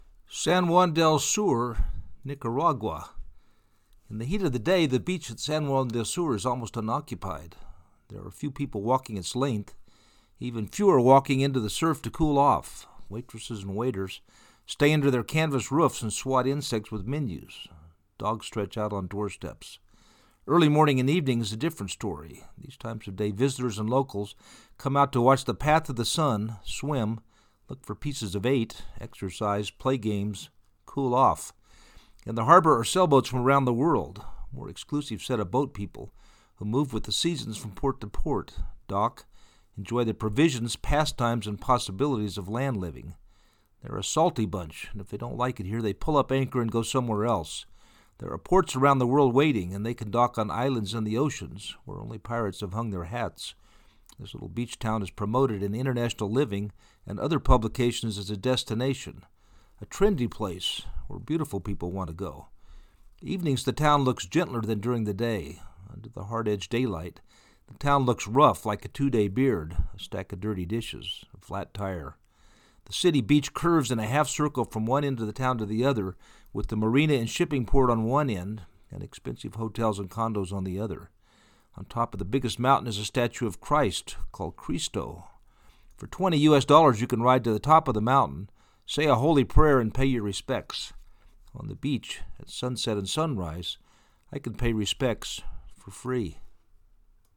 san-juan-del-sur-nicaragua.mp3